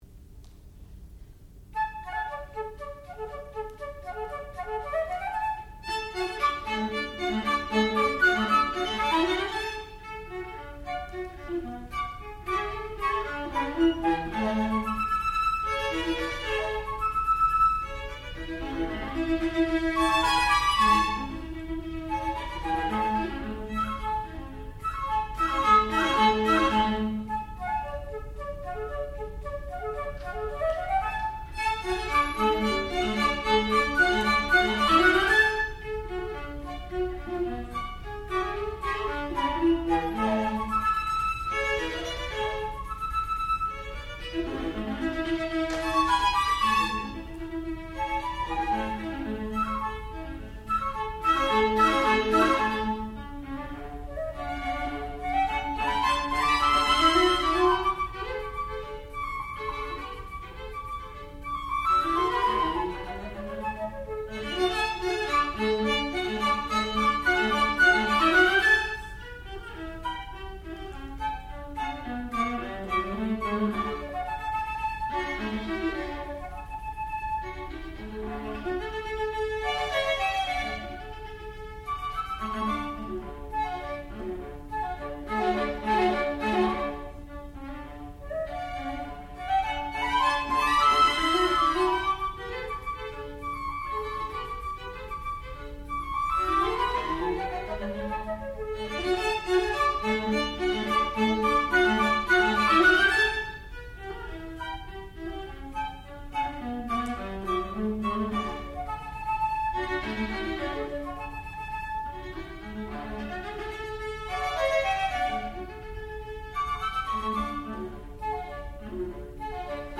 sound recording-musical
classical music
Advanced Degree Recital